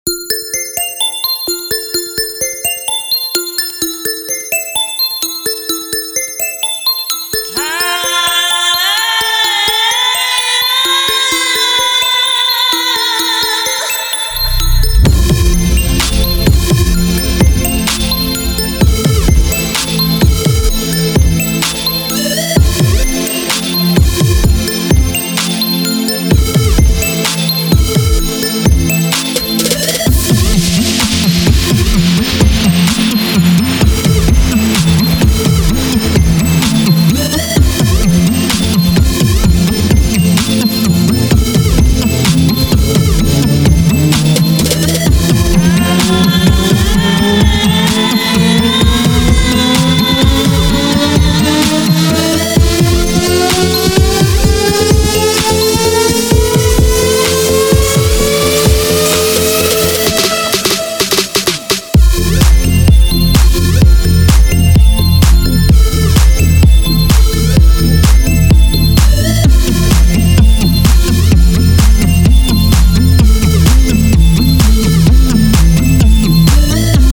• Качество: 320, Stereo
Electronic
EDM
без слов
нарастающие
house
колокольчики
Приятные колокольчики